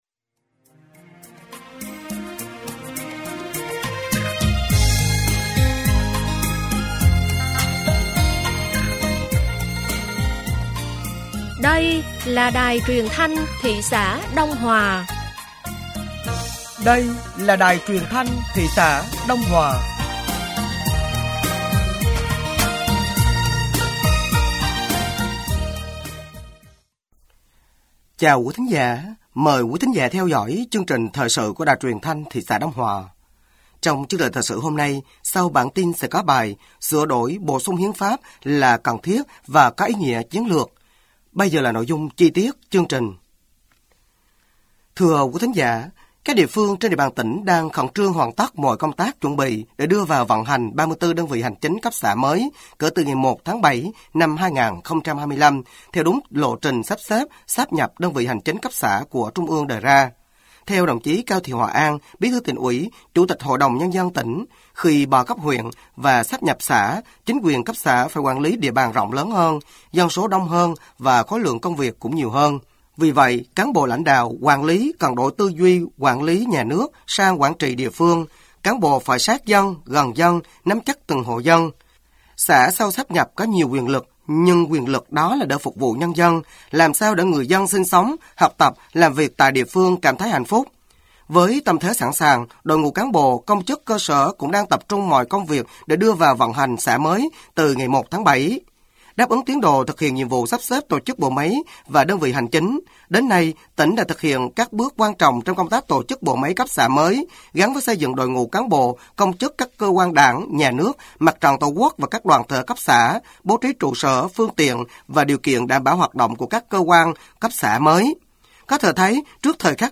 Thời sự tối ngày 18/6/2025 sáng ngày 19/6/2025